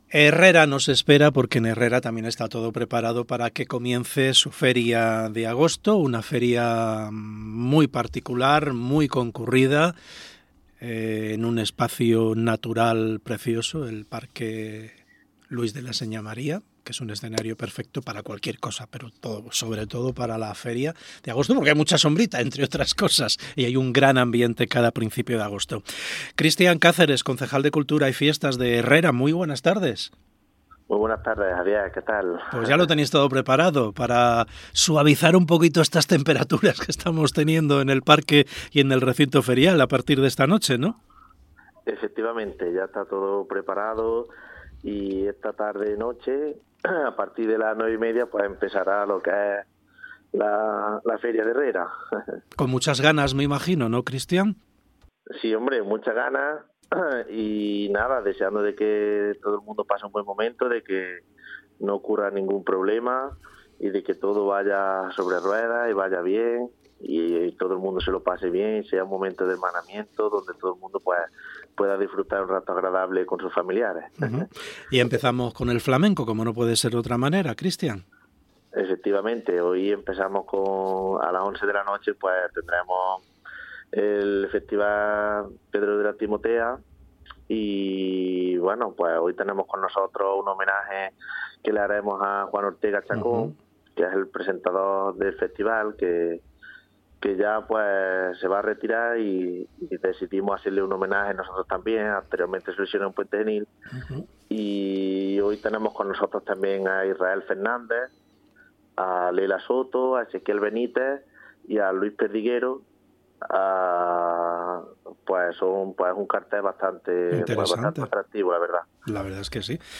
Entrevista Cristian Cáceres, Feria de Herrera 2025 - Andalucía Centro